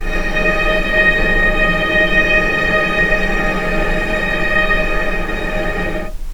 vc_sp-D#5-pp.AIF